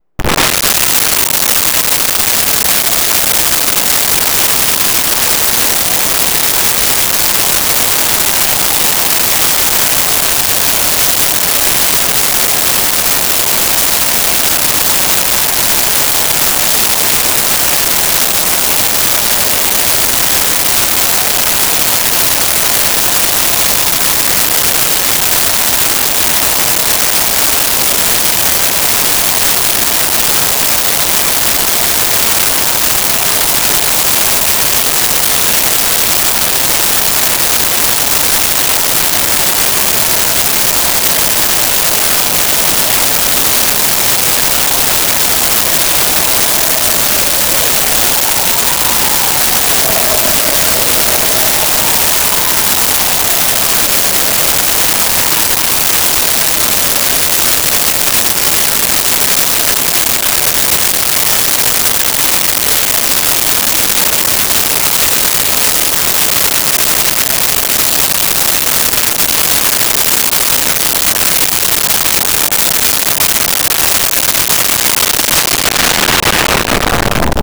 City Traffic Police Siren
City Traffic Police Siren.wav